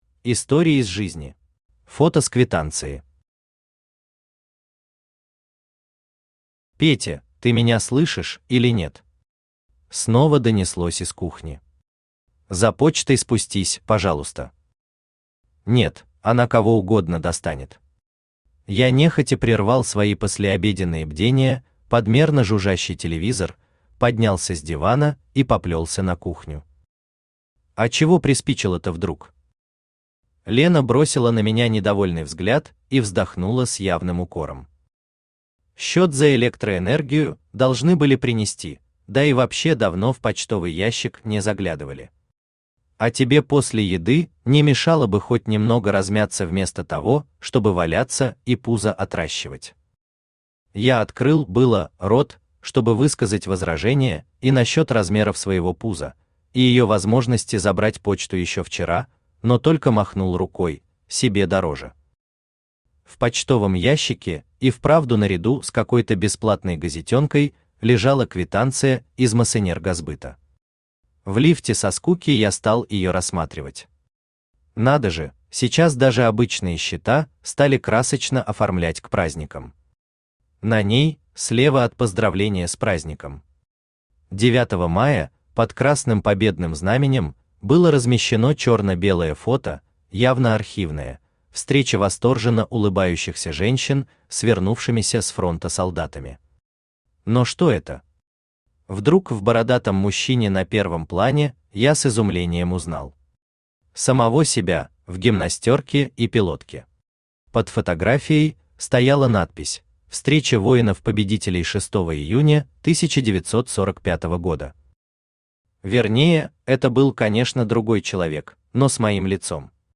Сборник рассказов Автор Аркадий Неминов Читает аудиокнигу Авточтец ЛитРес.